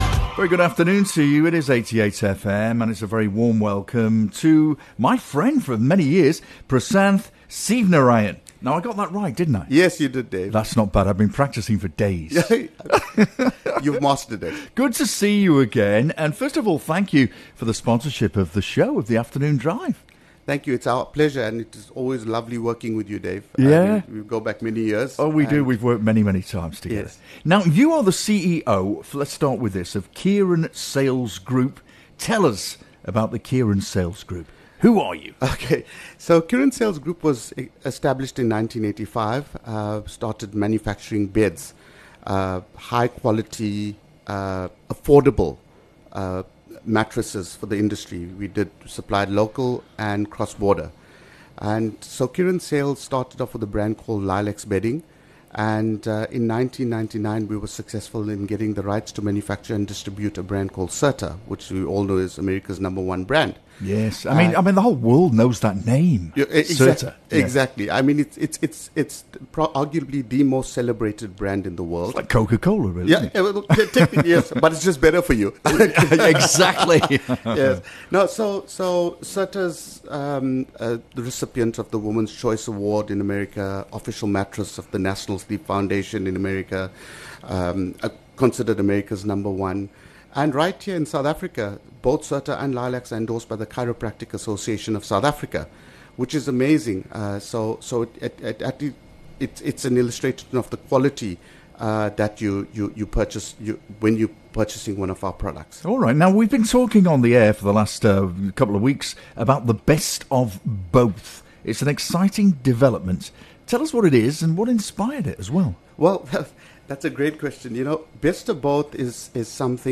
9 Oct Live from Blue Rock: Redefining the Future of Sleep 🛏✨
Join us live from Blue Rock Premium Mattress Outlet, where Kiran Sales Group is redefining sleep through innovation, quality, and luxury - featuring top brands like Serta and Lylax, cutting-edge spring systems, and immersive in-store experiences.